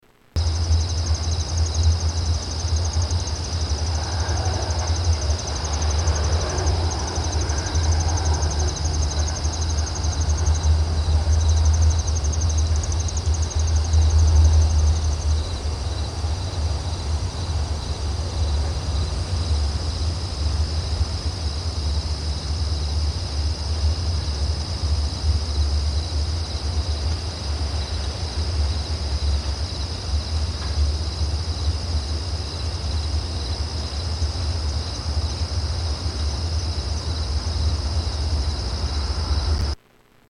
Crickets Sound on Cape Cod 2